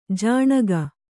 ♪ jāṇaga